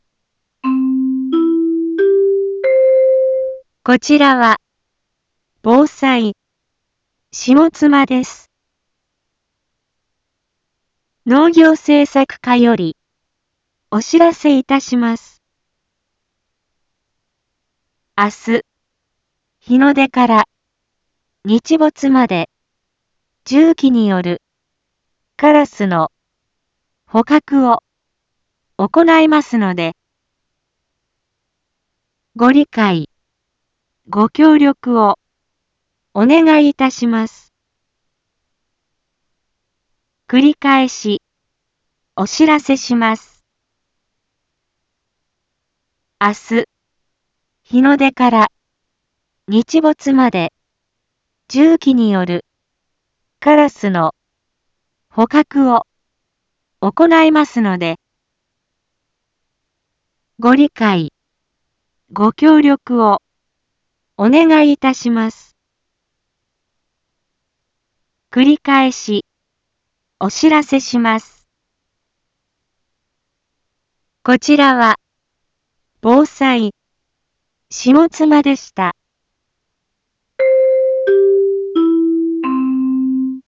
一般放送情報
Back Home 一般放送情報 音声放送 再生 一般放送情報 登録日時：2023-12-02 18:01:24 タイトル：有害鳥捕獲についてのお知らせ インフォメーション：こちらは、防災、下妻です。